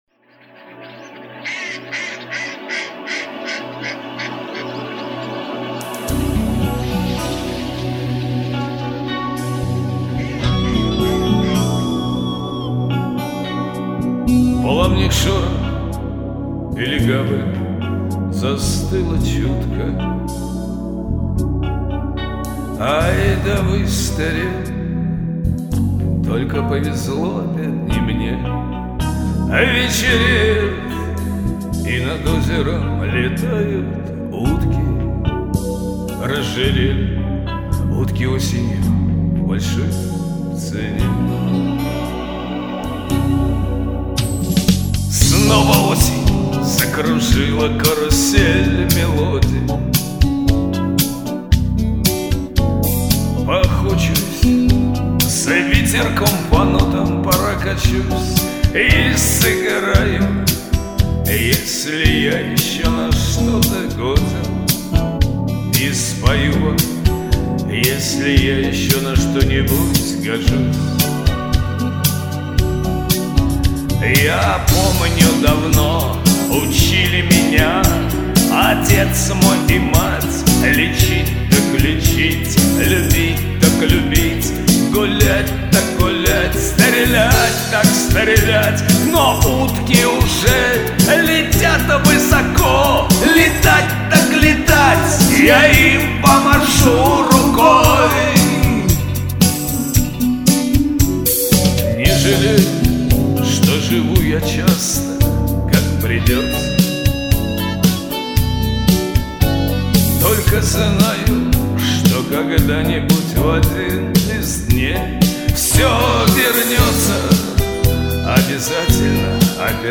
Записал с кондачка .Торопился.